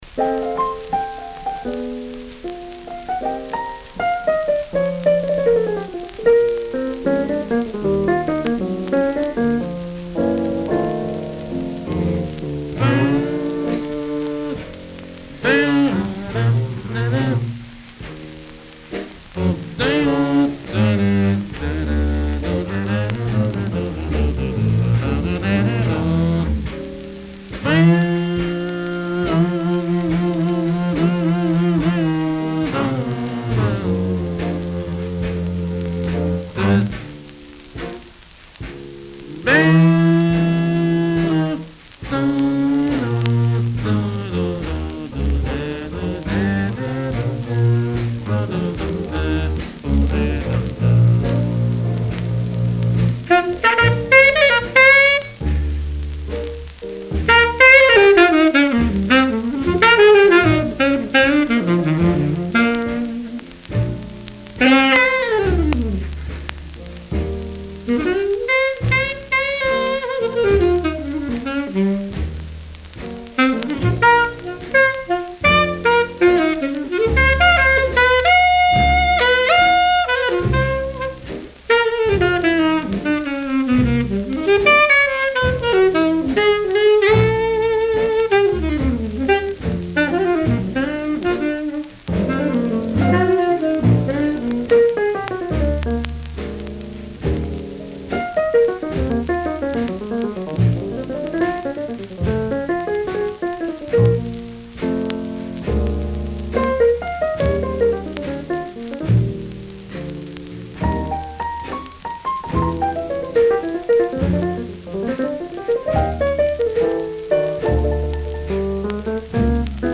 Recorded June 6, 1945 at WOR Studios NYC